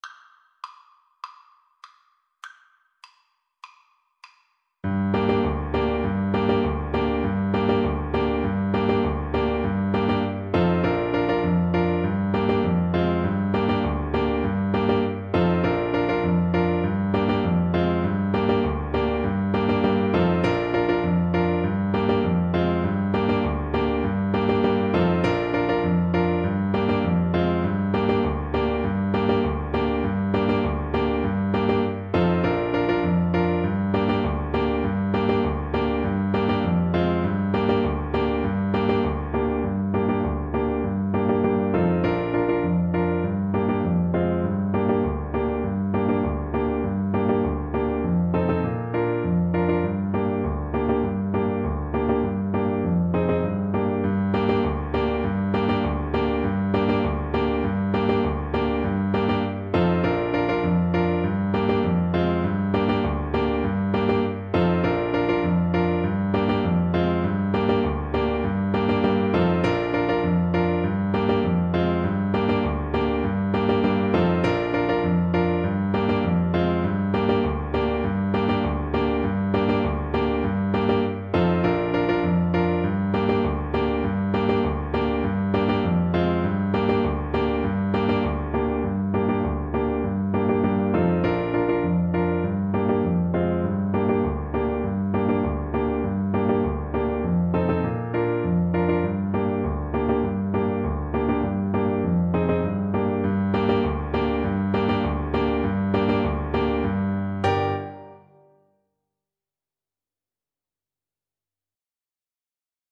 Play (or use space bar on your keyboard) Pause Music Playalong - Piano Accompaniment Playalong Band Accompaniment not yet available transpose reset tempo print settings full screen
Traditional Music of unknown author.
C minor (Sounding Pitch) D minor (Trumpet in Bb) (View more C minor Music for Trumpet )
Moderato
4/4 (View more 4/4 Music)